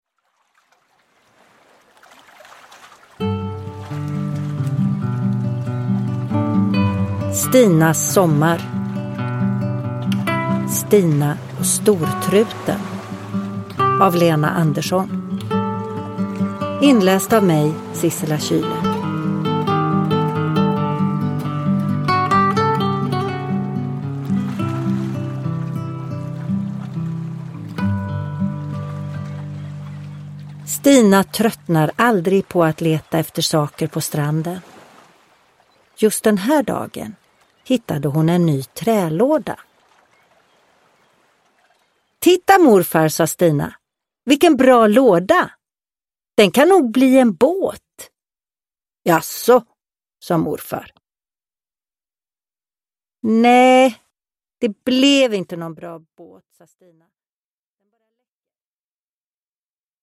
Stina och stortruten – Ljudbok – Laddas ner
Uppläsare: Sissela Kyle